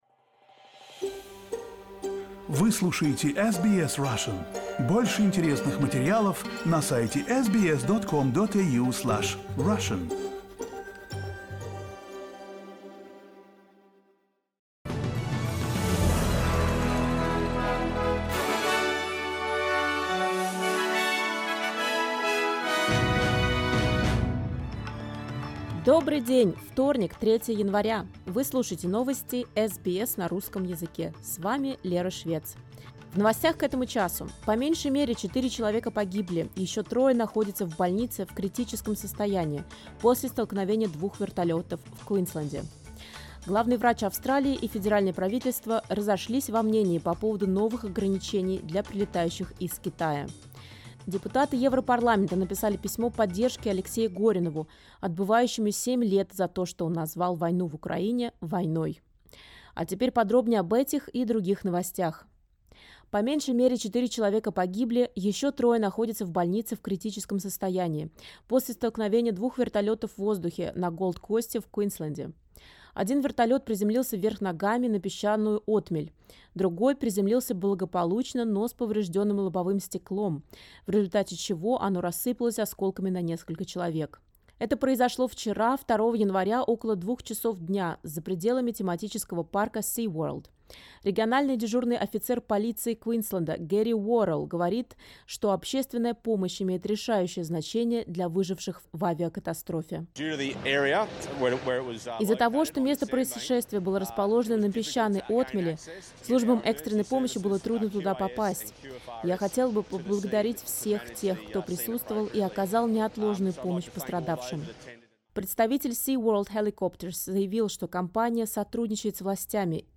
SBS news in Russian — 03.01.2023